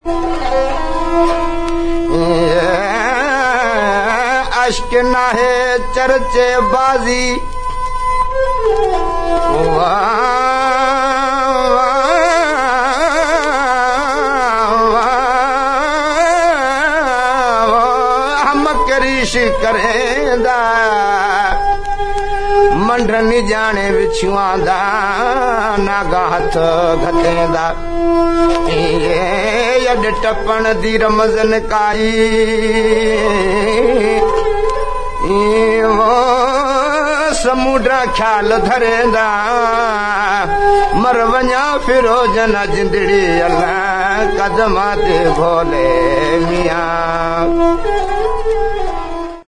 Stringed -> Bowed
ASIA -> INDIA
SARANGI
Sarangi arrunta da.